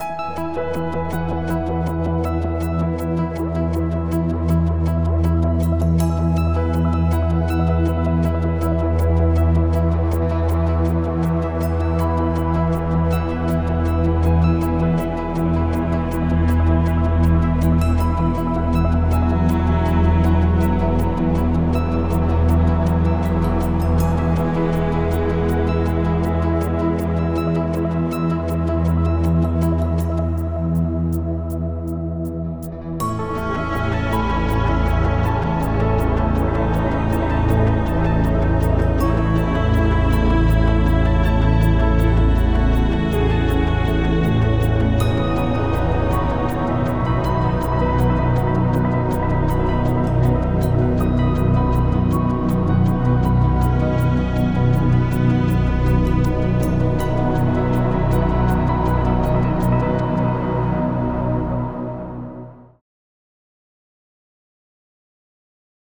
Added Ambient music pack. 2024-04-14 17:36:33 -04:00 18 MiB Raw History
Ambient Joy cut 60.wav